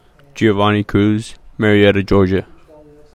PRONUNCIATION: